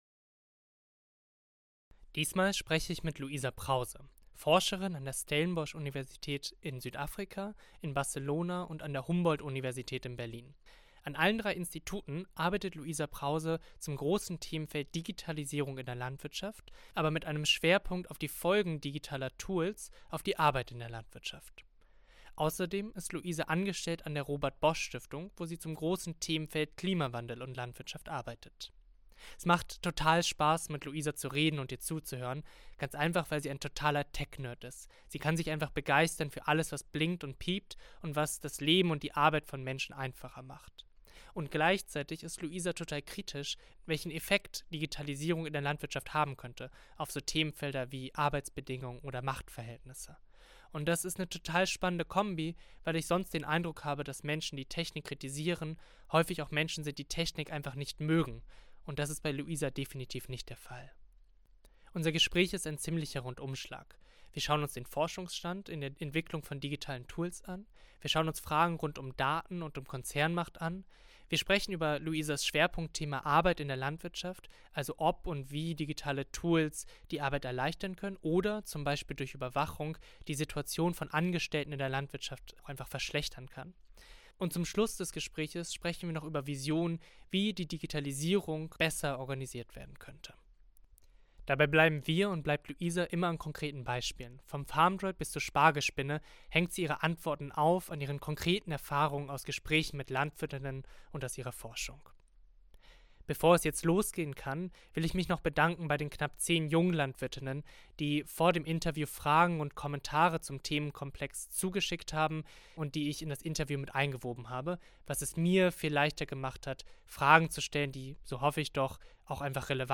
[Interview]